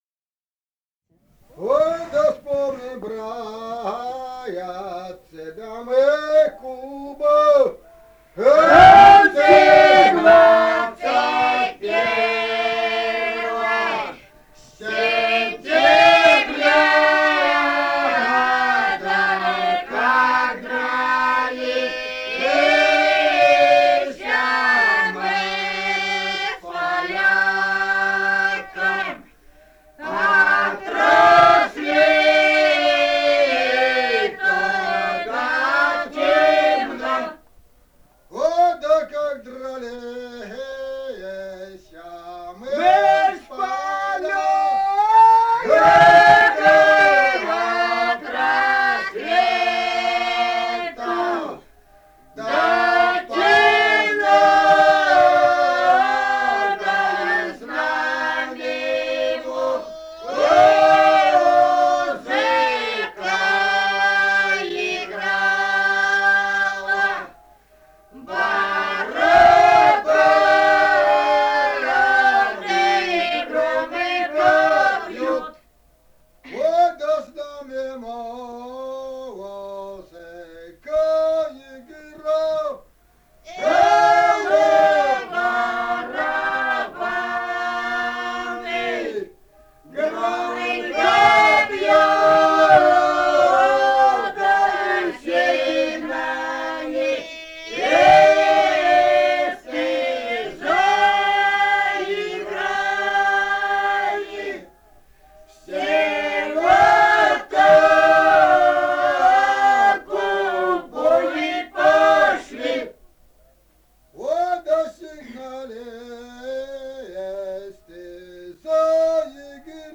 Этномузыкологические исследования и полевые материалы
Бурятия, с. Харацай Закаменского района, 1966 г. И0905-10